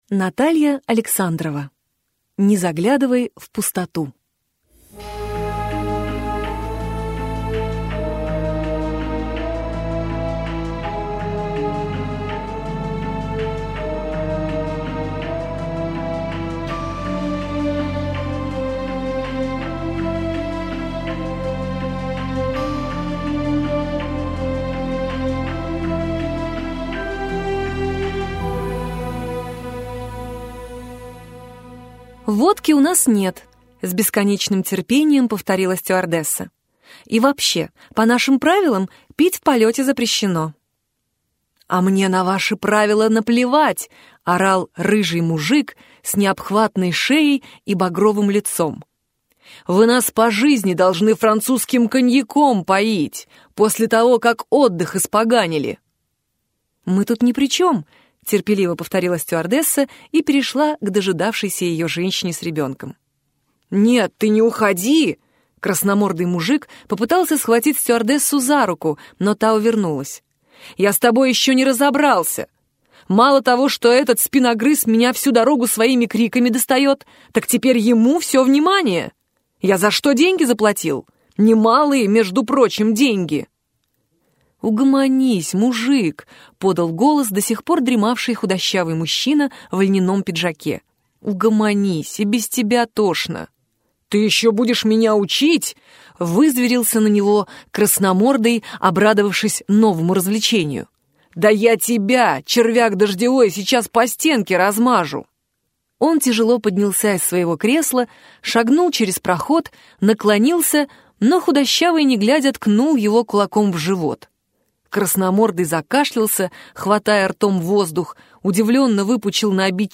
Аудиокнига Не заглядывай в пустоту | Библиотека аудиокниг
Прослушать и бесплатно скачать фрагмент аудиокниги